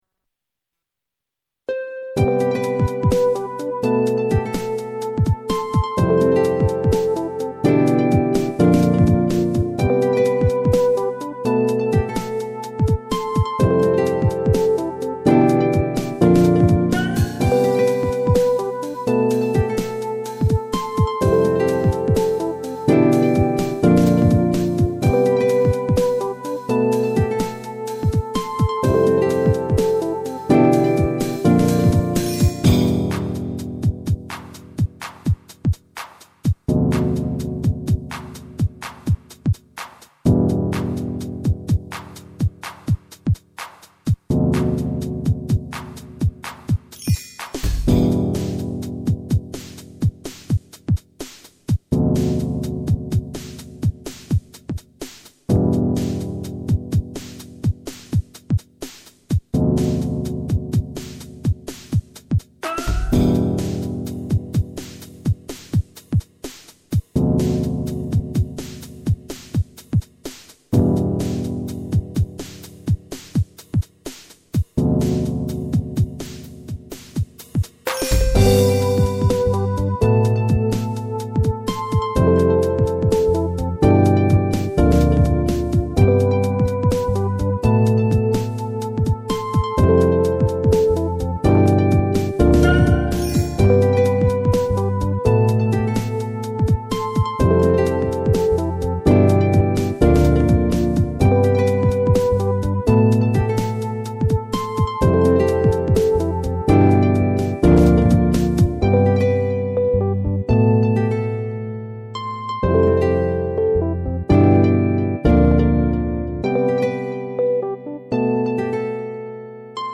Eesy listening
stepを目指して作られた曲。でも、２stepになりきれなかった曲。ほんのりボサ・ノヴァ風味。